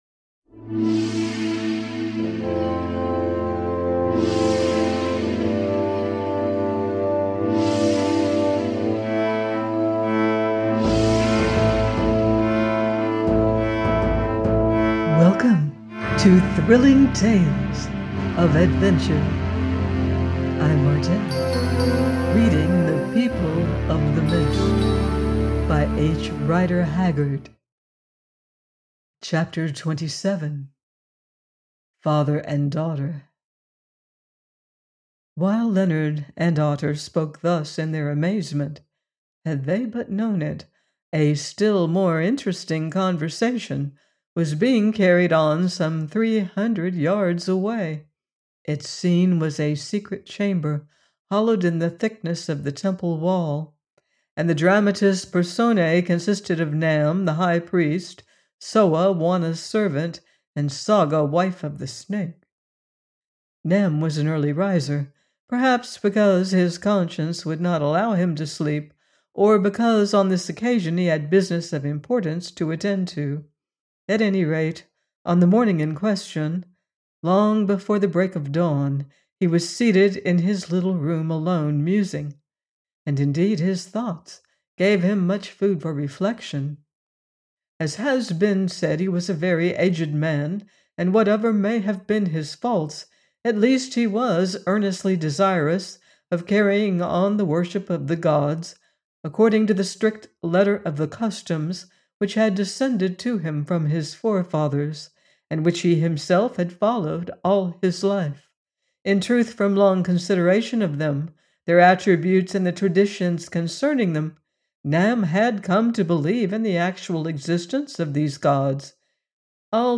The People Of The Mist – 27: by H. Rider Haggard - audiobook